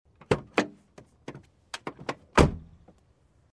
Звуки двери машины
Звук открытия и закрытия двери автомобиля (снаружи) (00:04)